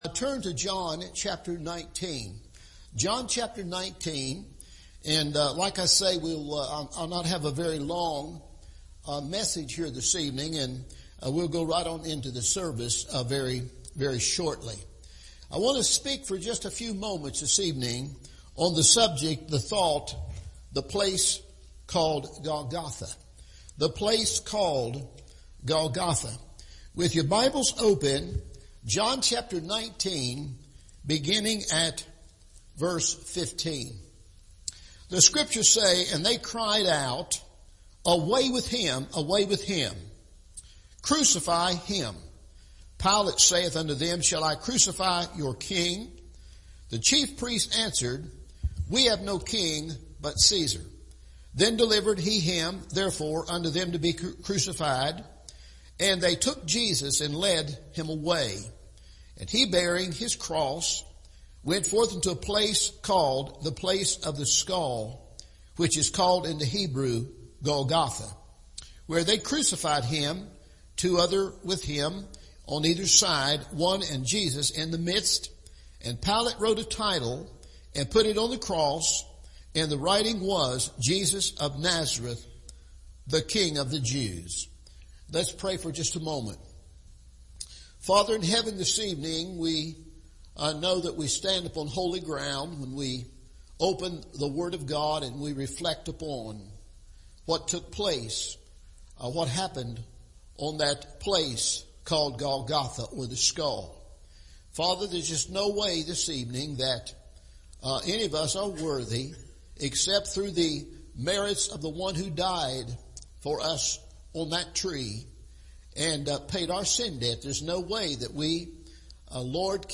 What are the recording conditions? The Place Called Golgotha – Evening Service